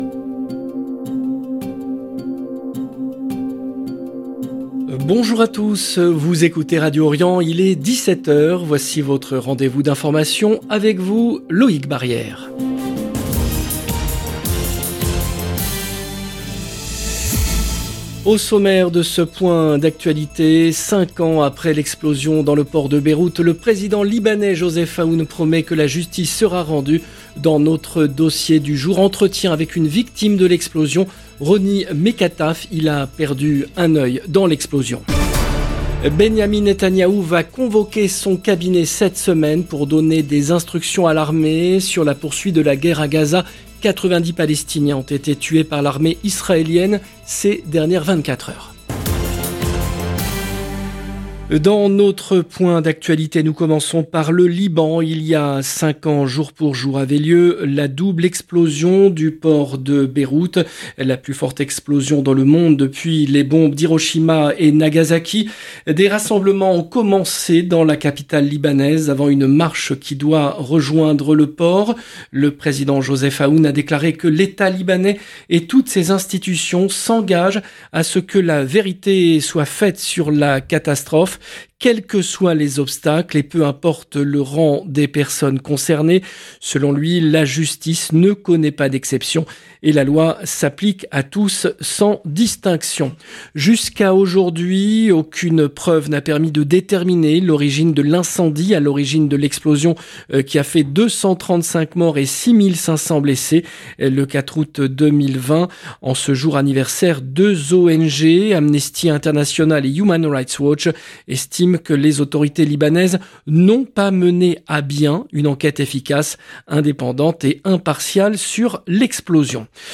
Magazine d'information du 4 août 2025